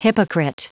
Prononciation du mot hypocrite en anglais (fichier audio)
Prononciation du mot : hypocrite